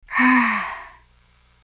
sigh1.wav